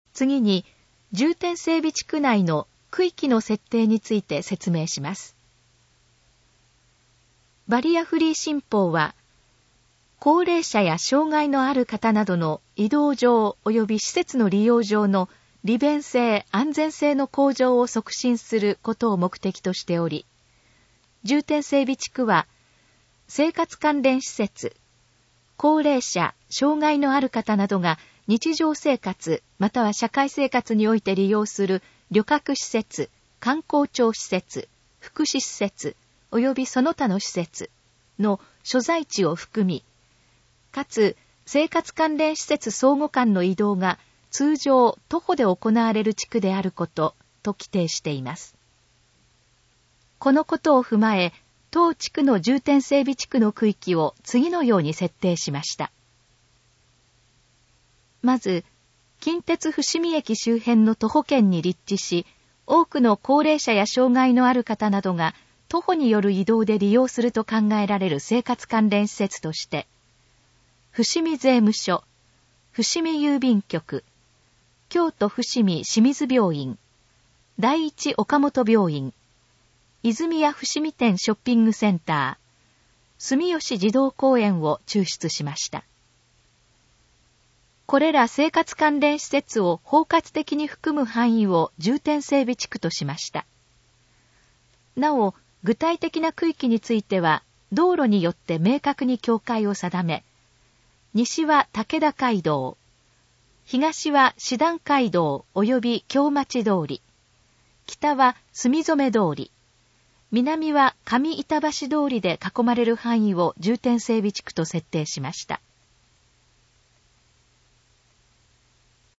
このページの要約を音声で読み上げます。
ナレーション再生 約466KB